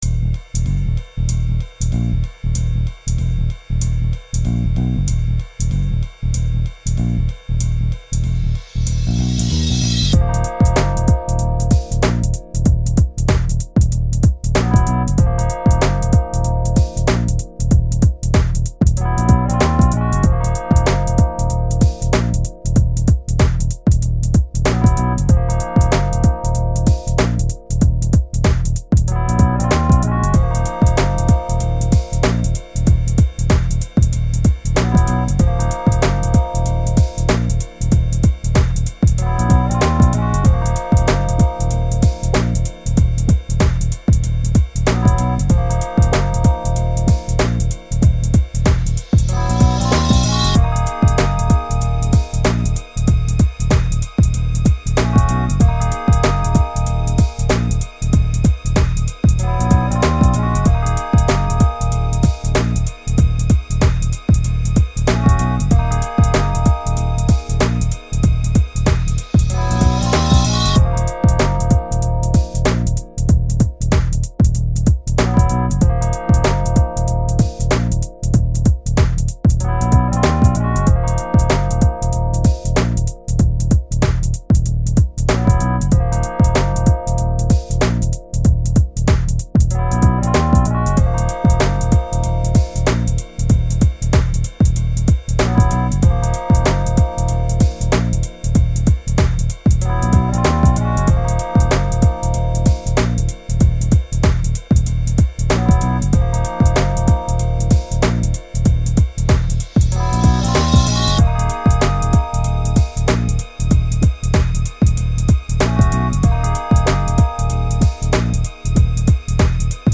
Hip-Hop and LoFi Beats
New Jack drums